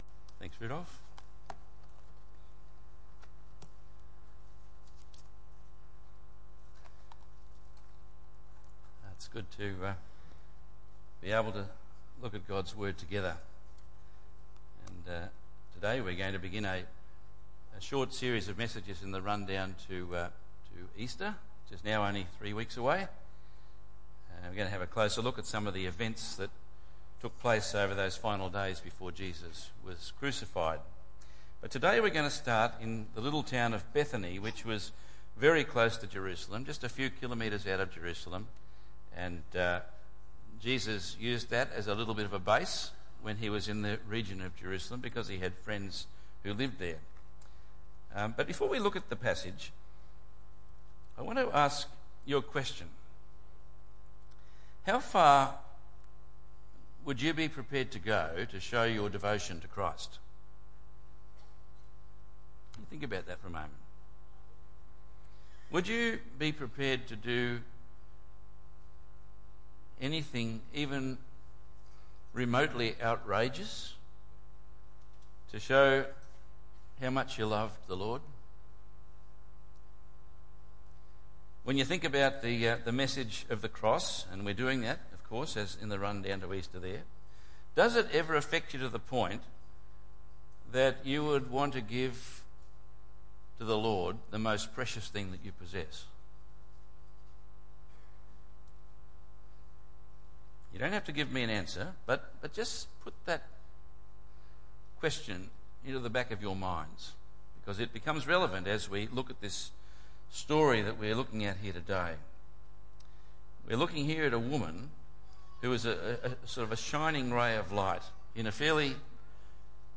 Matthew 26:6-6:13 Tagged with Sunday Morning